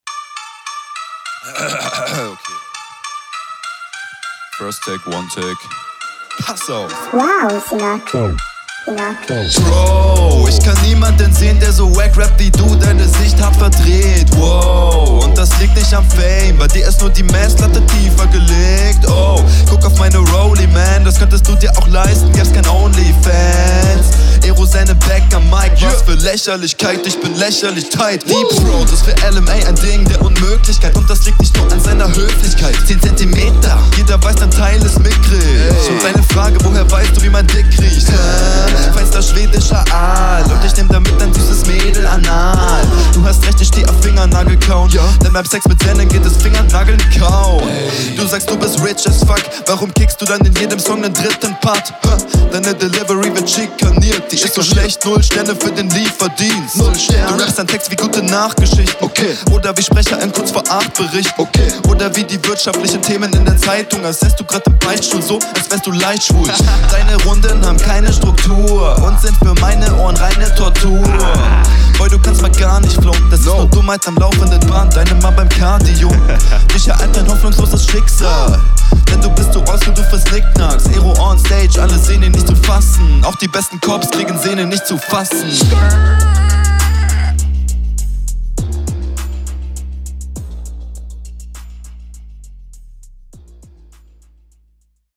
Flow: mkay flowtechnisch auch ganz solide.